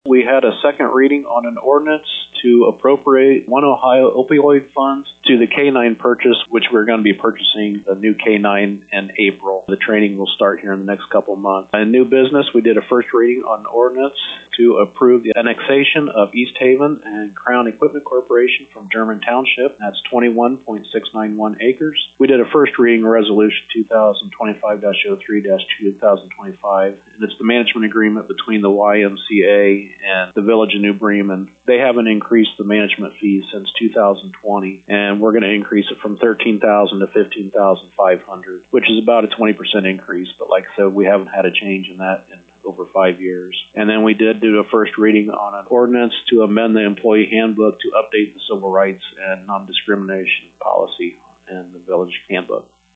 To hear a summary with New Bremen Mayor Bob Parker: